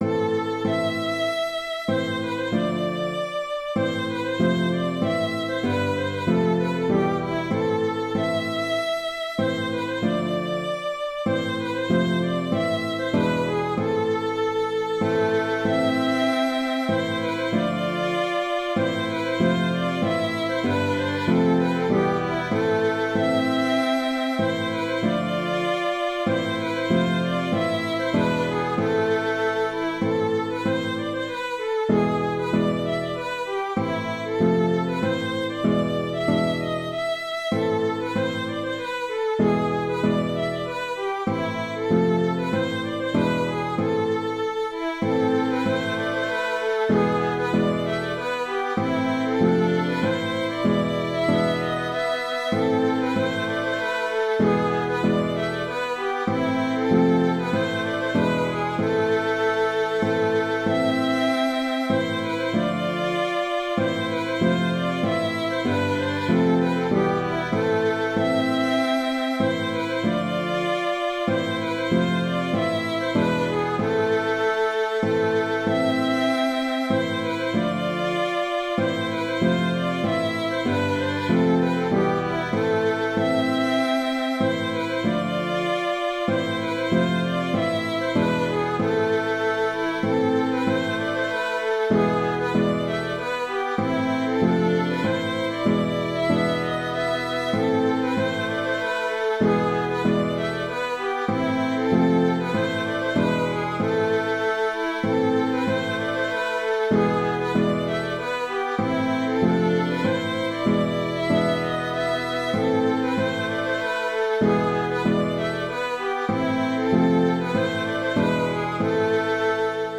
Polska efter Simas-Nicke (Polska) - Musique folk
Légende ou pas, elle a traversé les siècles avec sans doute différentes manières de danser. Danse à trois temps, elle se caractérise par un tempo assez lent et un 2e temps appuyé qui la distingue de la valse.